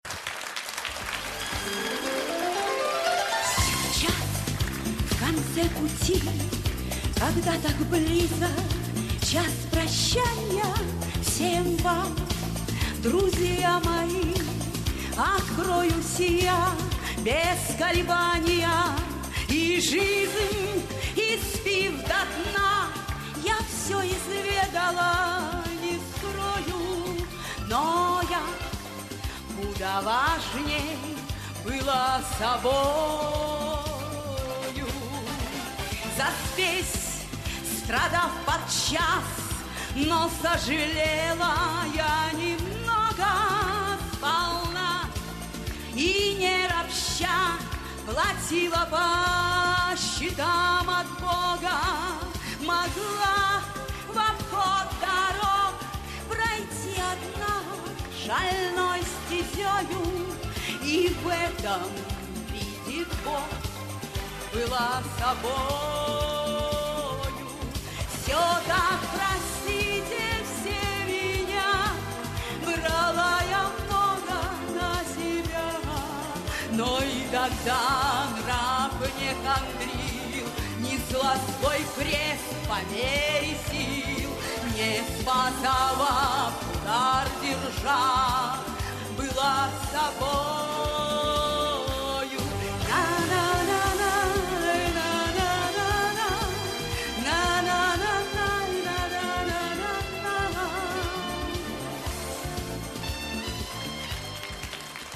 в женском исполнении